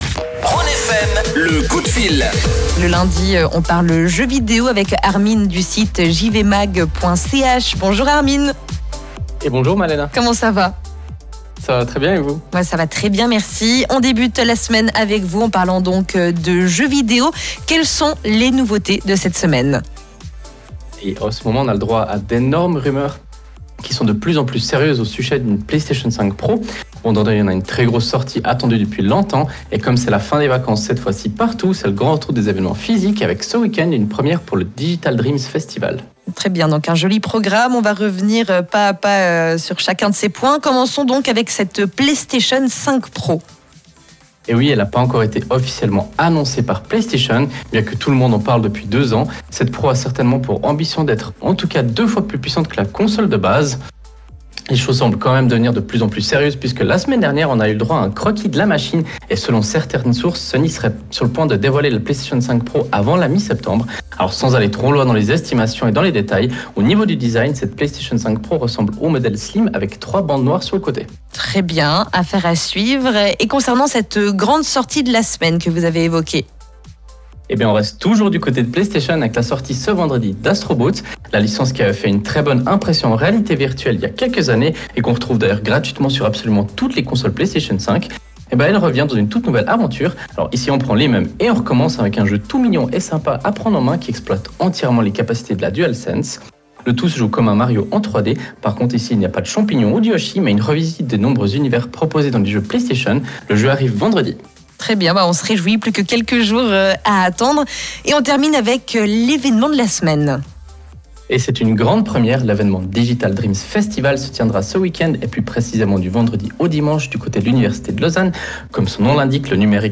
Comme tous les lundis, nous avons la chance de présenter notre chronique gaming sur la radio Rhône FM.
Vous pouvez réécouter le direct Rhône FM via le flux qui se trouve juste en haut de l’article.